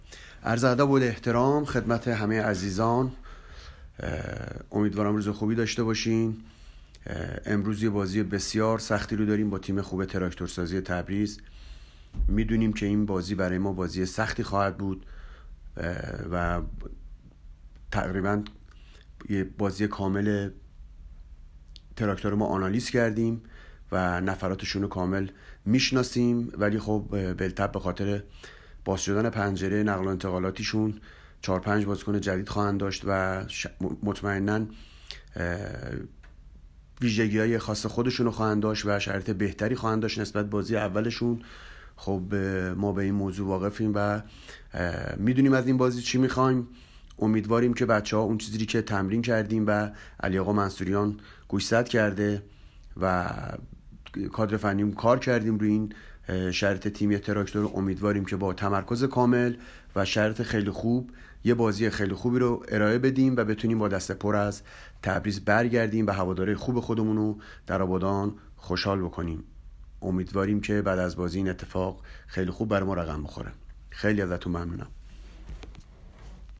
برچسب ها: نشست خبری ، لیگ برتر ، فوتبال